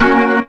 B3 FMAJ 2.wav